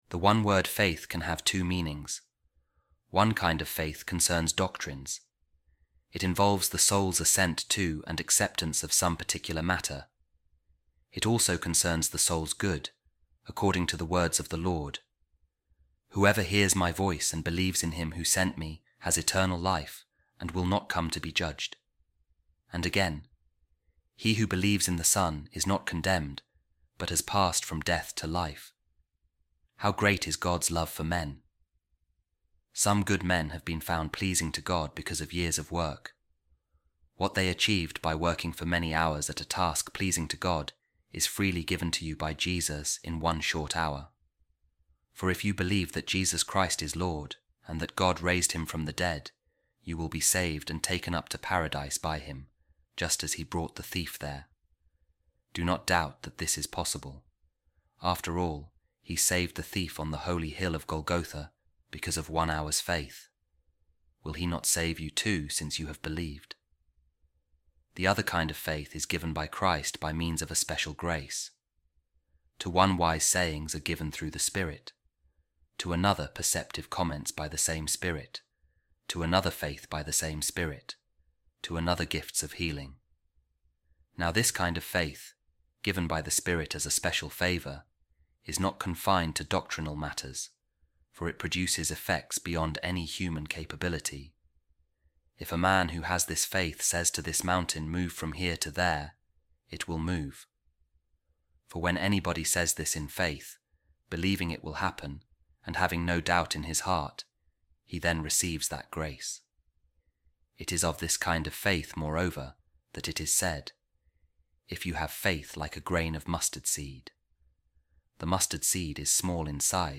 A Reading From The Instructions Of Saint Cyril Of Jerusalem To Catechumens | The Power Of Faith Transcends Man’s Powers